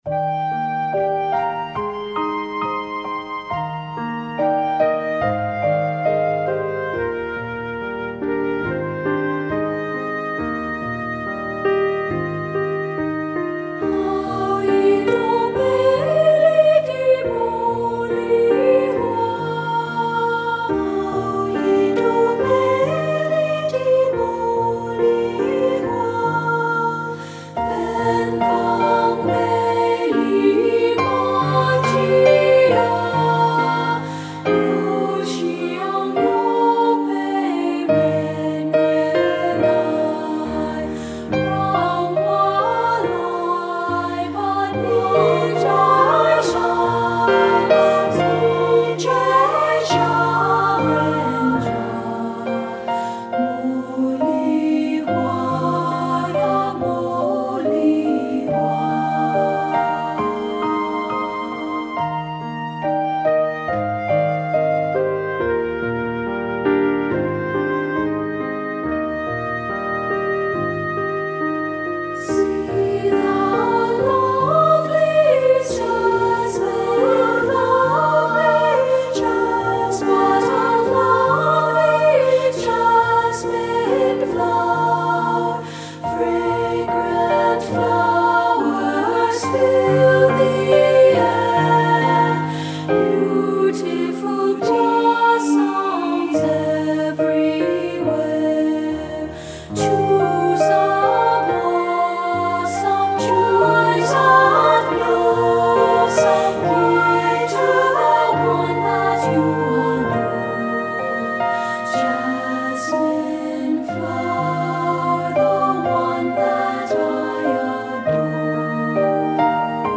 Composer: Chinese Folk Song
Voicing: 2-Part